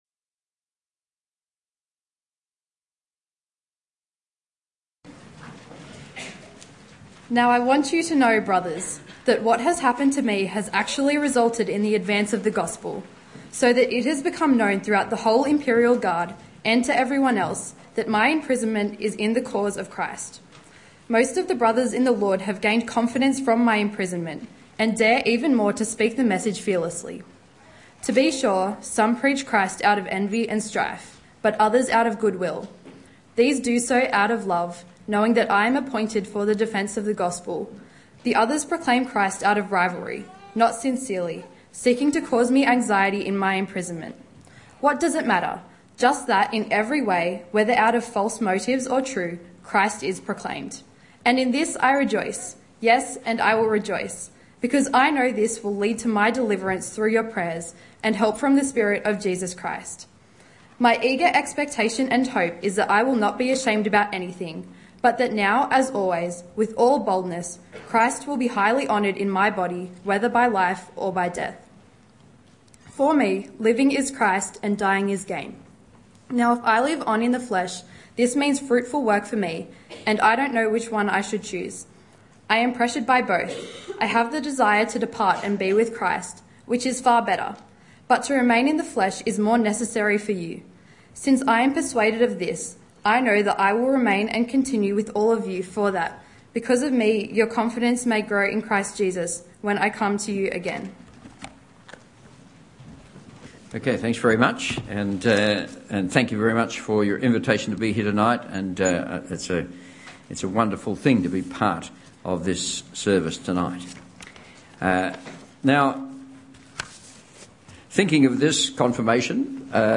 Confirmation Service 2019: Living Is Christ, Dying Is Gain - Philippians 1:12-26
Evening Church - Dr Peter Jensen - Sunday 31 March 2019